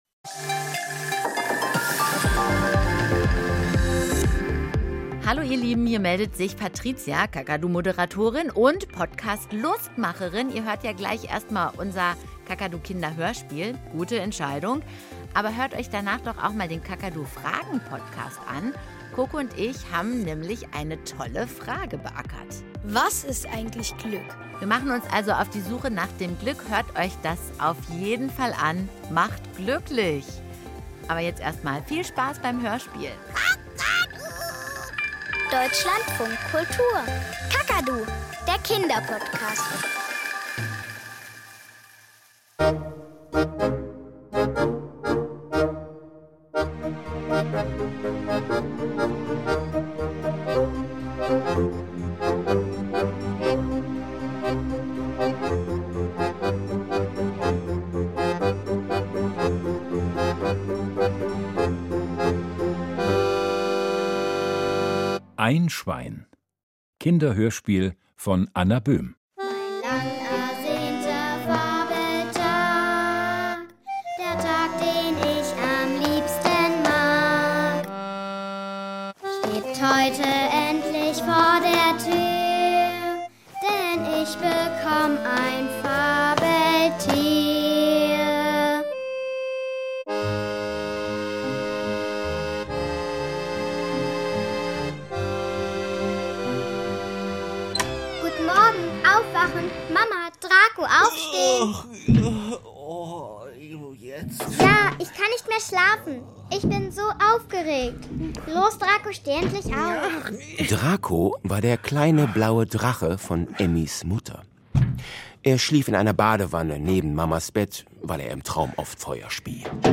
Hörspiele kostenlos online hören: Einschwein -